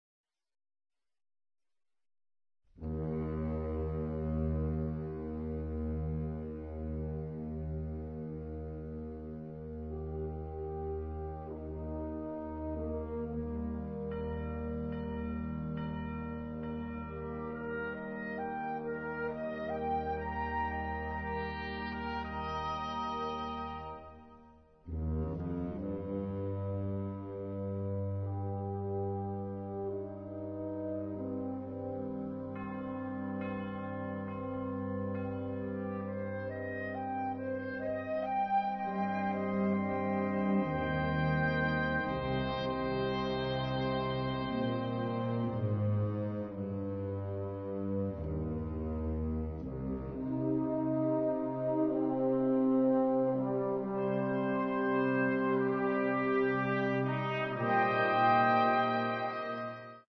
Category Concert/wind/brass band
Subcategory Mourning music
Instrumentation Ha (concert/wind band)